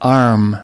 2. arm /ɑːrm/: cánh tay